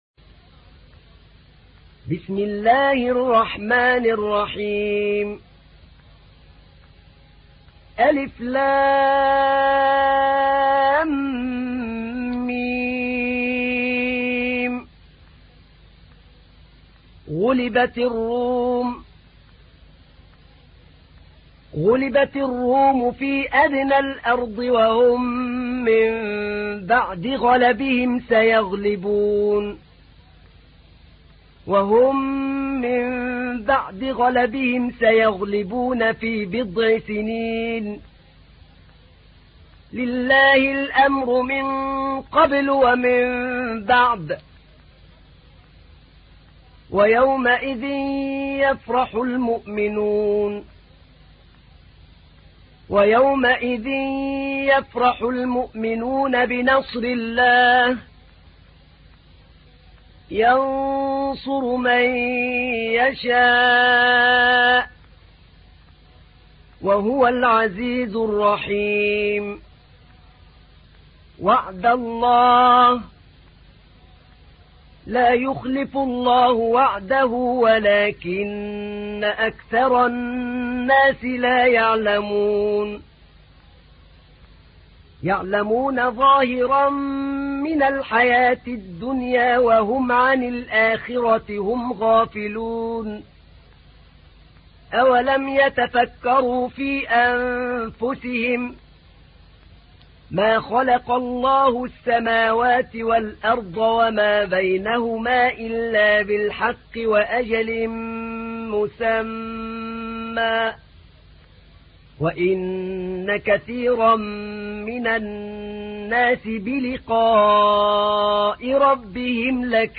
تحميل : 30. سورة الروم / القارئ أحمد نعينع / القرآن الكريم / موقع يا حسين